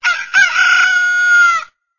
دانلود آهنگ خروس برای اس ام اس از افکت صوتی انسان و موجودات زنده
جلوه های صوتی
دانلود صدای خروس برای اس ام اس از ساعد نیوز با لینک مستقیم و کیفیت بالا